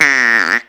Duck call 07.wav